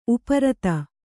♪ uparata